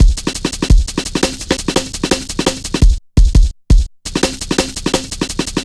Index of /90_sSampleCDs/Zero-G - Total Drum Bass/Drumloops - 3/track 57 (170bpm)